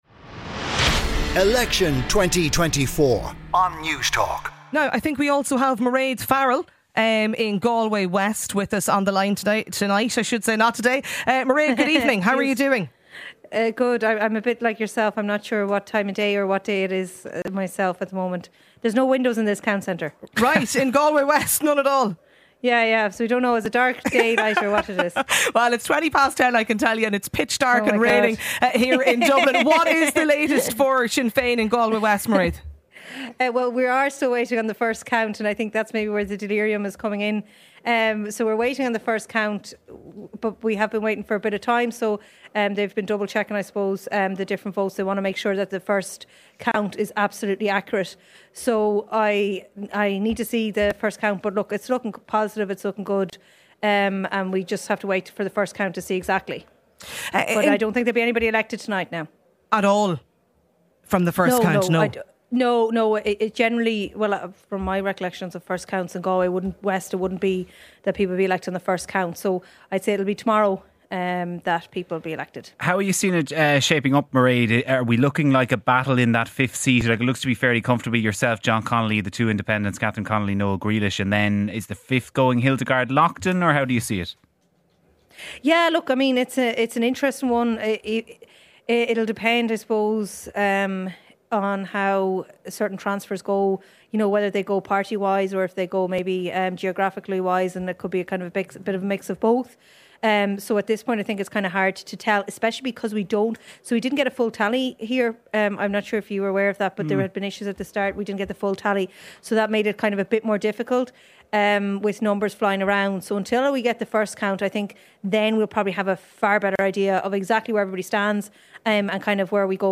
Ivan Yates was joined by newly elected Fianna Fáil TD for Claire, Timmy Dooley to chat on his and his party's success in the 2024 general election, why the greens have been wiped and why he believes Michael Martin is the ideal next Taoiseach.